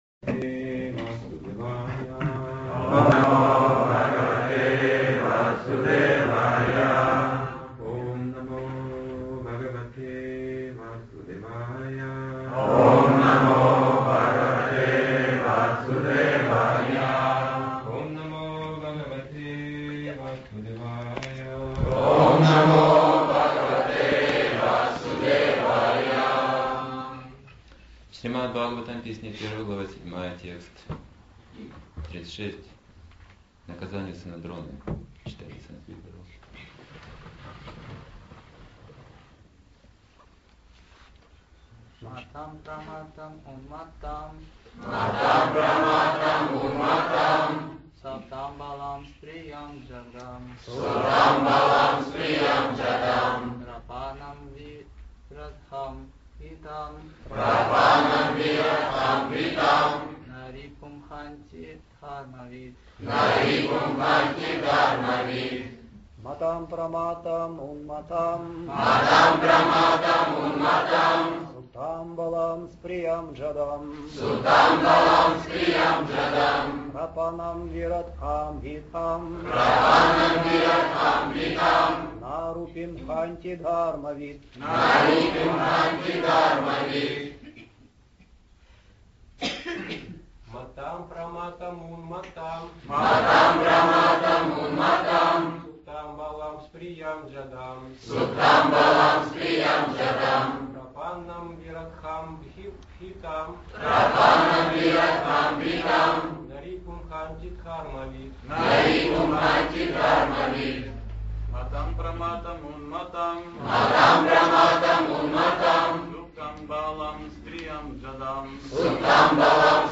Темы, затронутые в лекции